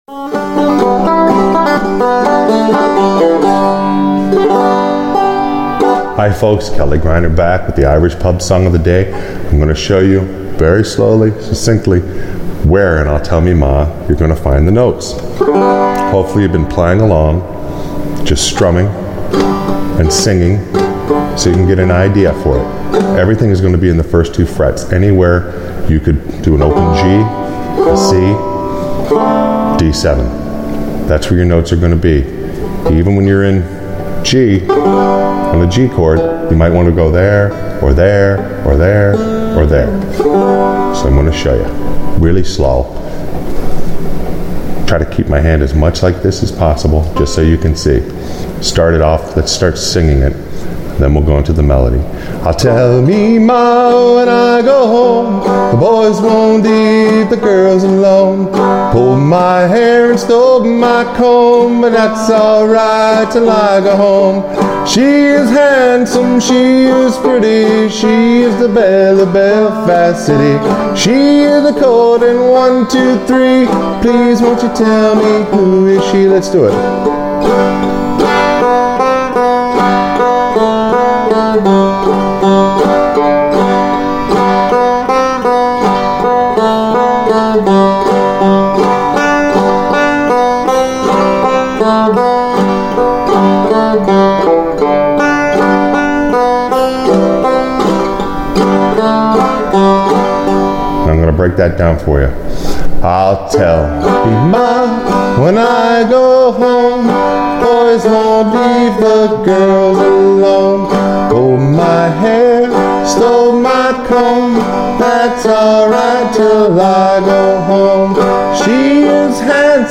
Irish Pub Song Of The Day – I’ll Tell Me Ma – Simple Melody